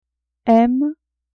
m emme emm